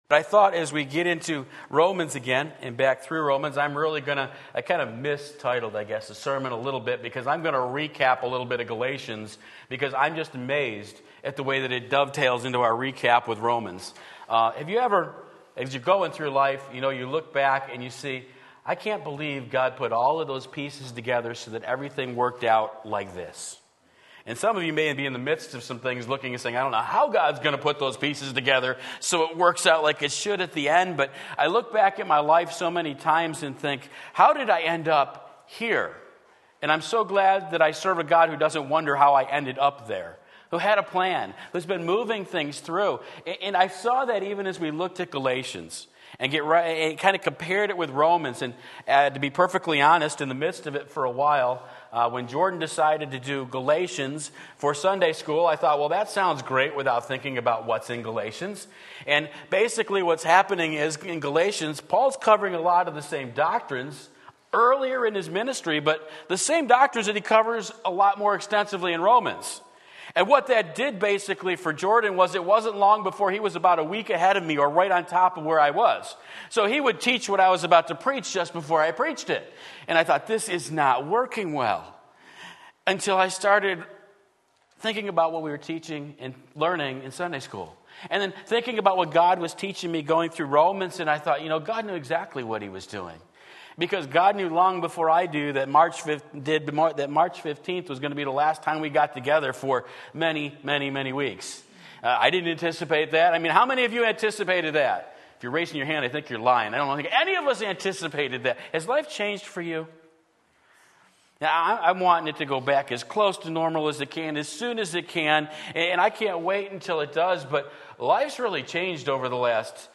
Sermon Link
5 Various References Sunday Morning Service